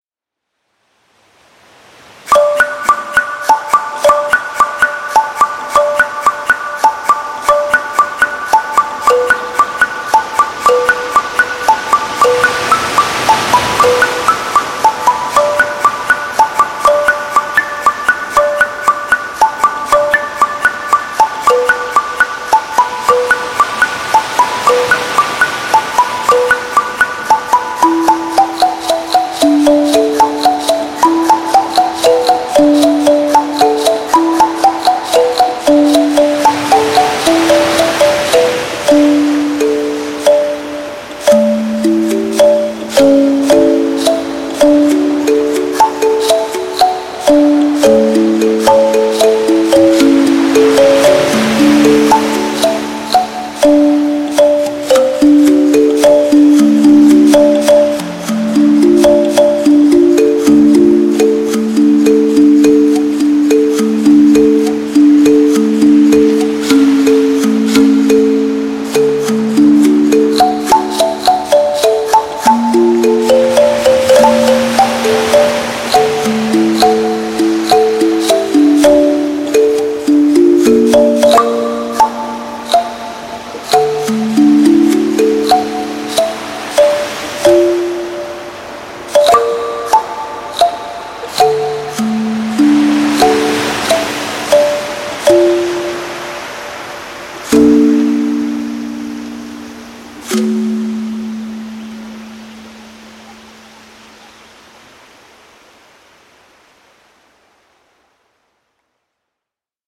• Качество: Хорошее
• Жанр: Детские песни
🎶 Детские песни / Музыка детям 🎵 / Музыка для новорожденных